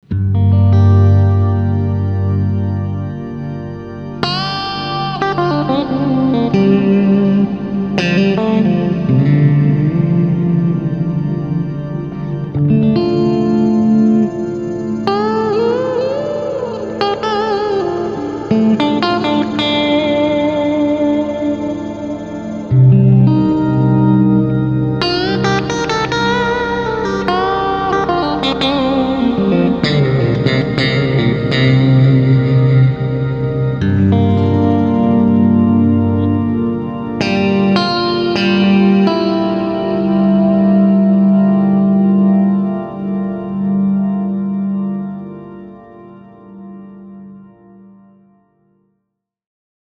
Freeze with Octave Blend Pad
POG3Polyphonic-Octave-Generator-Freeze-with-Blend-Pad.wav